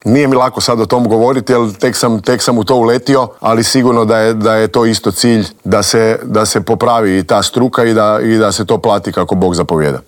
Iz godine u godinu se nadamo da će nam taj naš sport krenuti uzlaznom putanjom, a na čelo kao novi sportski direktor Hrvatskog košarkaškog saveza došao je tek umirovljeni igrač, Krunoslav Simon, koji je bio gost Intervjua tjedna Media servisa.